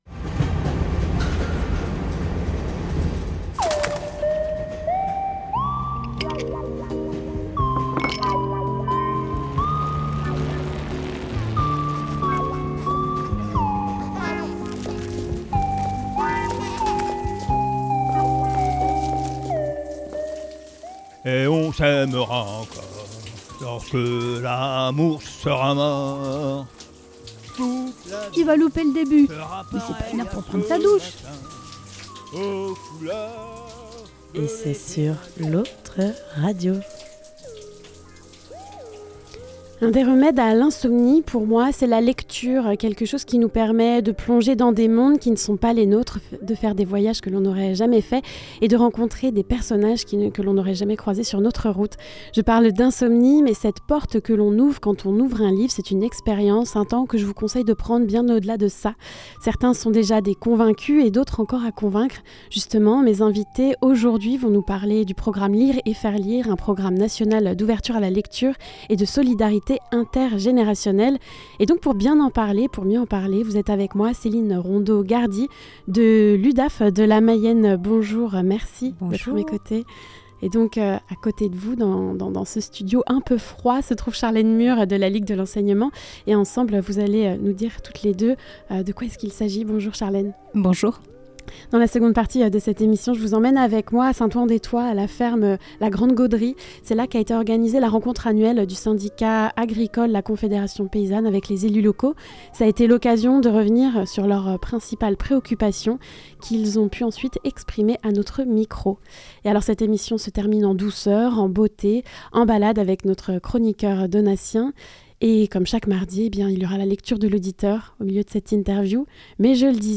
La lecture de l'auditeur